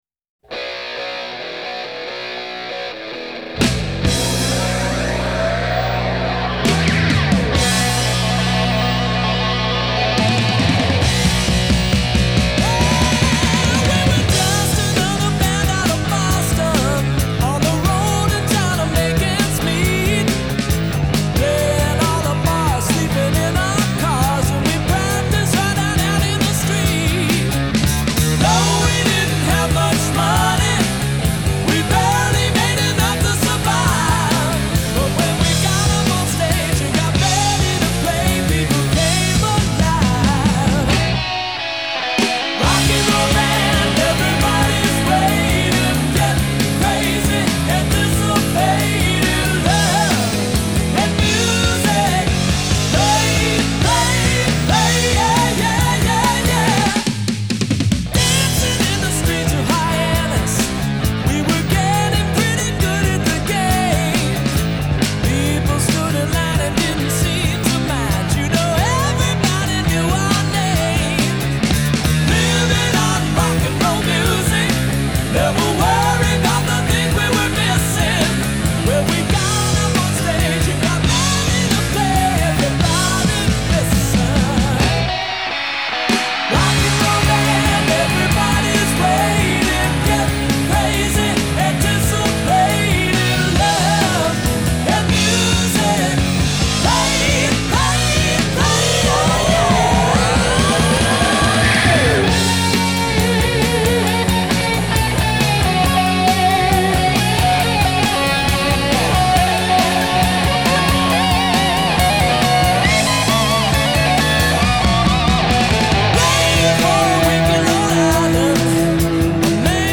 Genero: Rock
Calidad: Stereo (Exelente)(Copia de CD original)